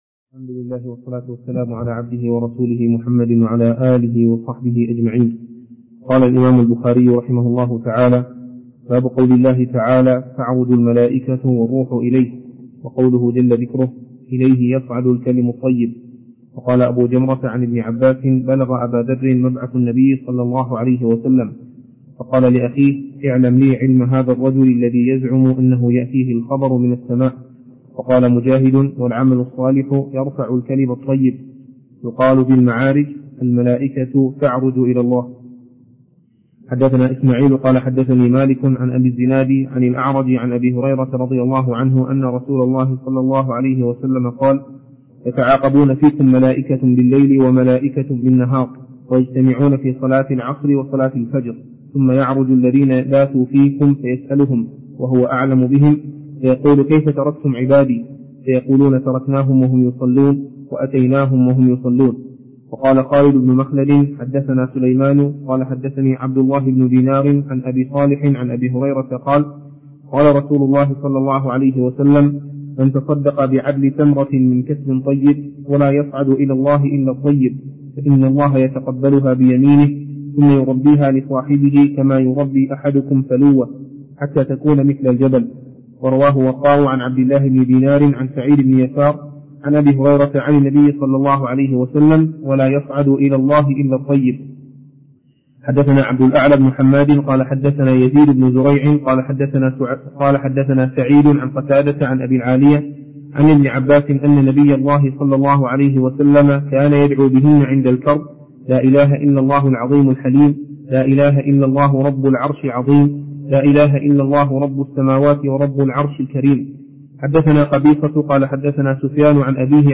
الدرس (5) شرح كتاب التوحيد من صحيح البخاري